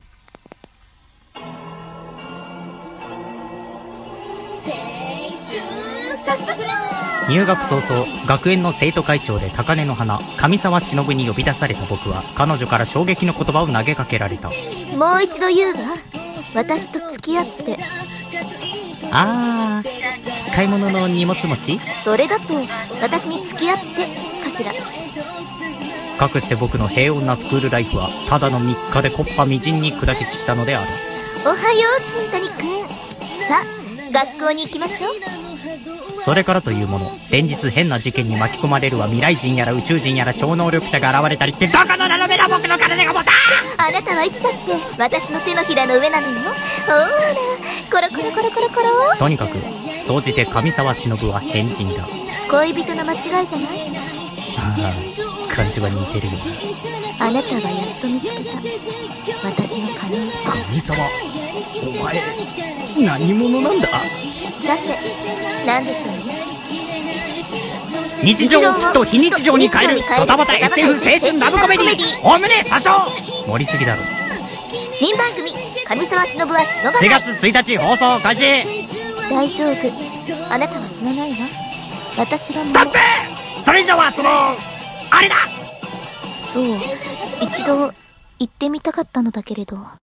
【アニメCM風声劇】『上沢忍はしのばない！』【二人声劇】